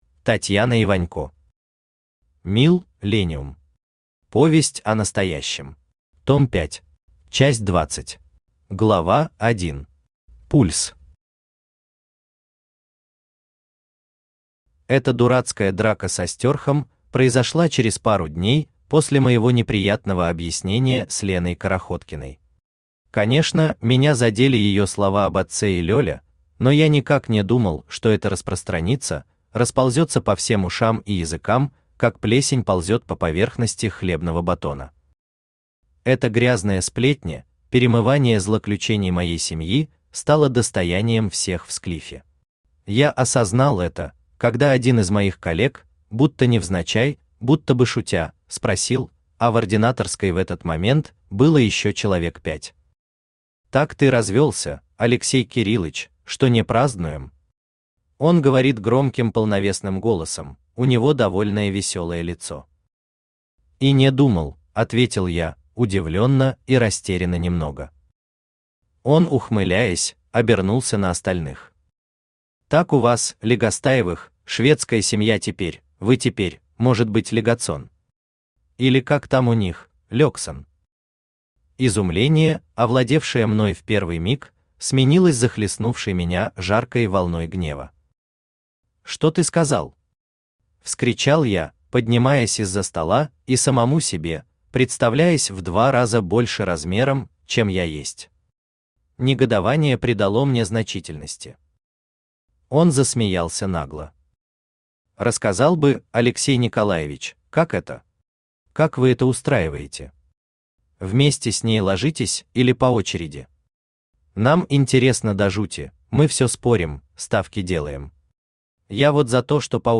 Аудиокнига МилЛЕниум. Повесть о настоящем. Том 5 | Библиотека аудиокниг
Том 5 Автор Татьяна Вячеславовна Иванько Читает аудиокнигу Авточтец ЛитРес.